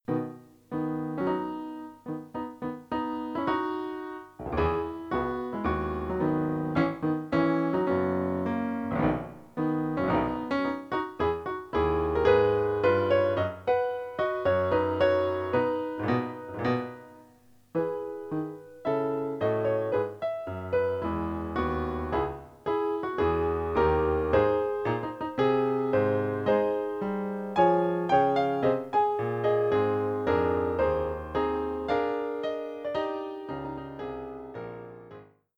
Instrumentation: Piano